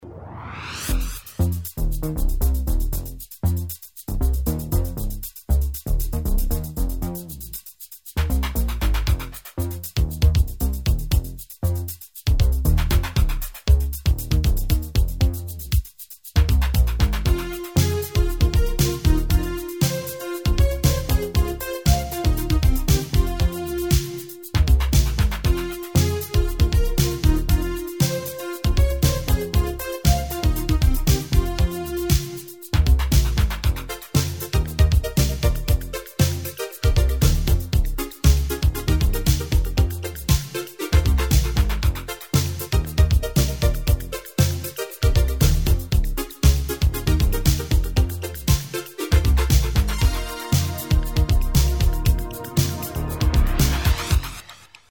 纯音乐版